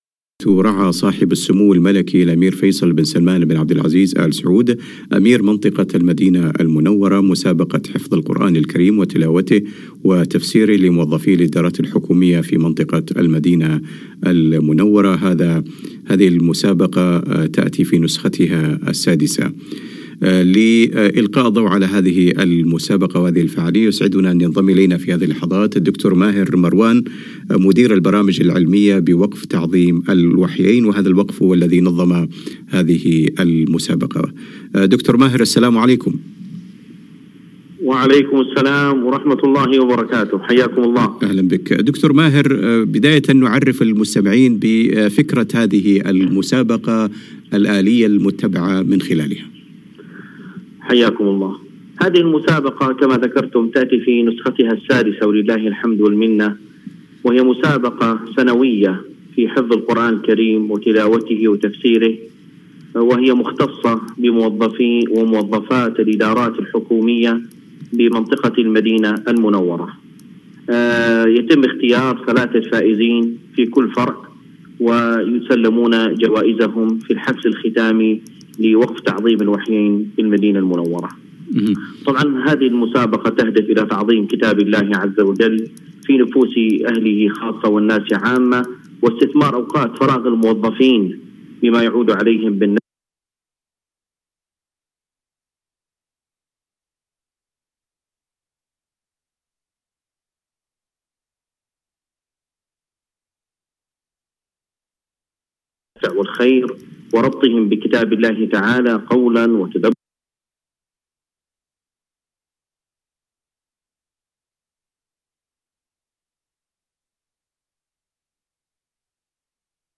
لقاء إذاعي
بإذاعة القرآن الكريم بالرياض.